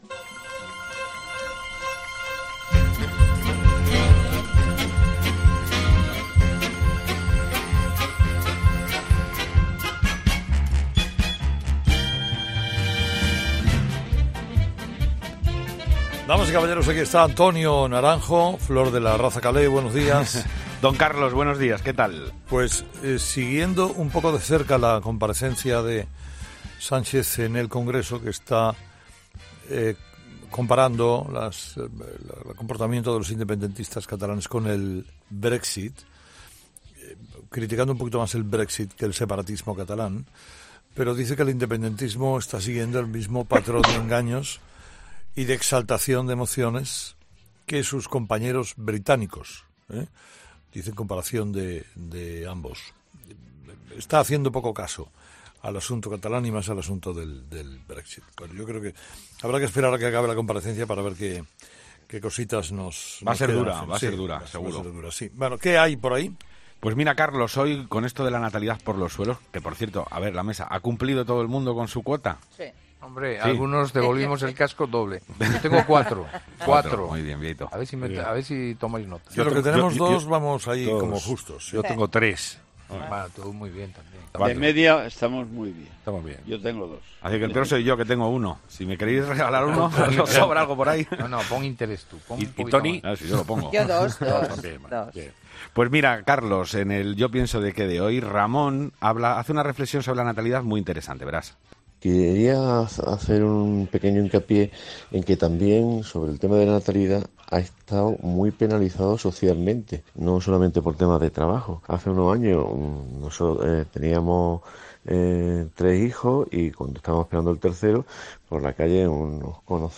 'La tertulia de los oyentes' es el espacio que Carlos Herrera da a sus seguidores para que se conviertan en un tertuliano más del programa.